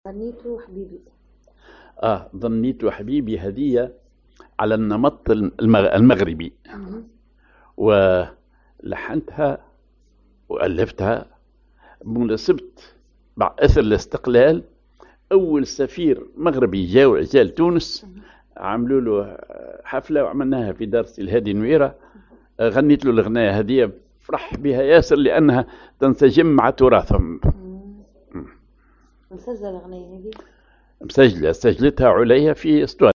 ar الحجاز
أغنية